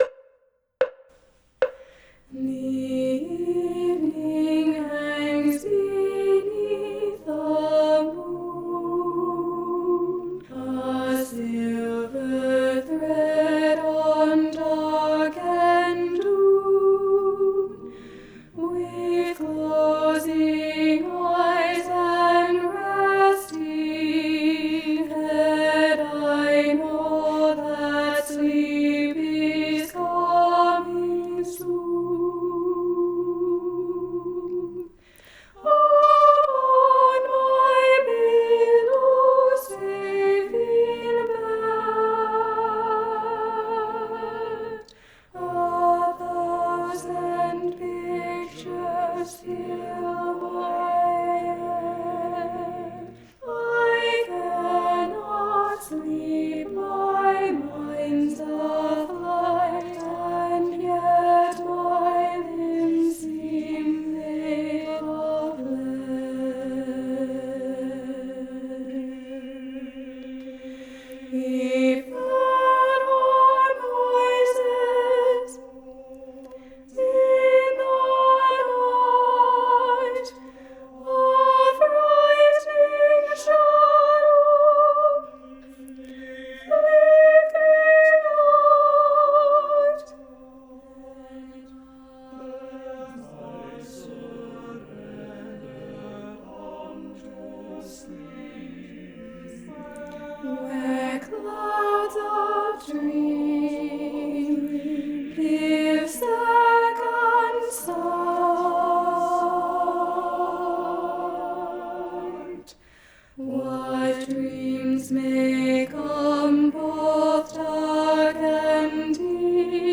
- Œuvre pour chœur à 8 voix mixtes (SSAATTBB)
Soprano 2 Live Vocal Practice Track